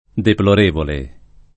deplor%vole] (meno com. deplorabile [deplor#bile]) agg. — oggi quasi solo -evole per «da condannare» (es. condotta d., eccessi d.); accanto a -evole, d’uso lett. -abile per «da compiangere» — cfr. deprecabile